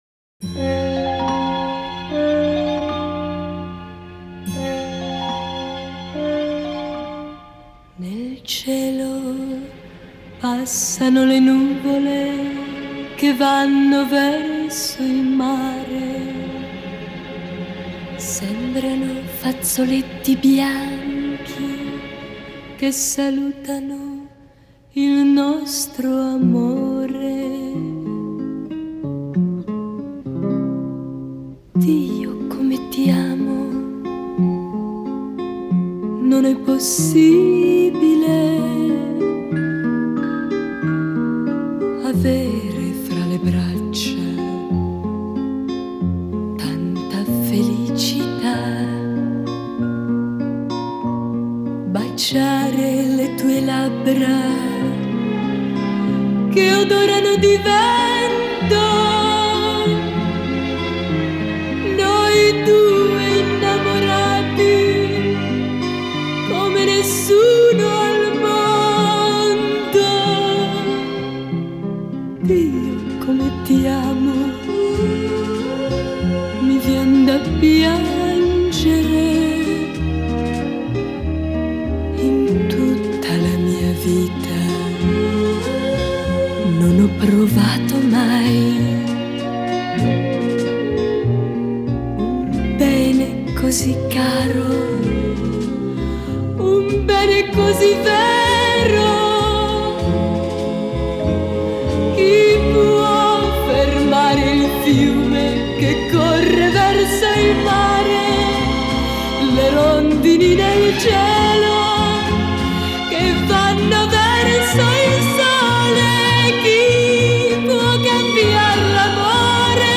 Genre: Pop, oldies